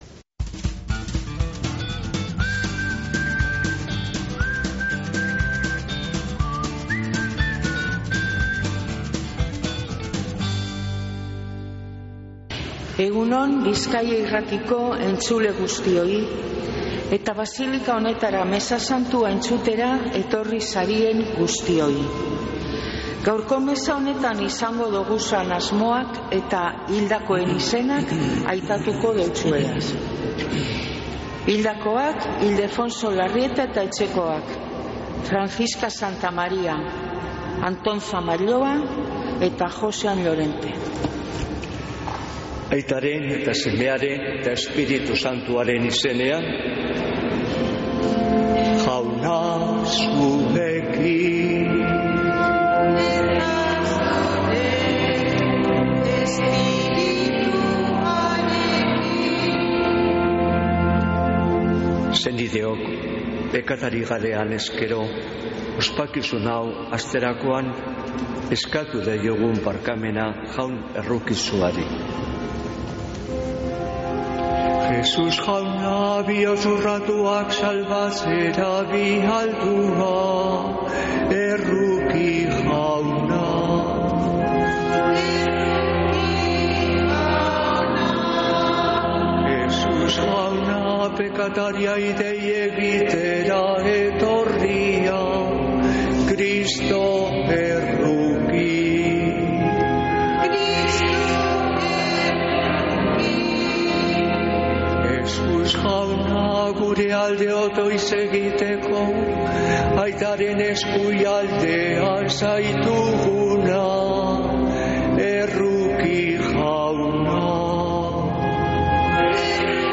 Mezea Begoñako basilikatik | Bizkaia Irratia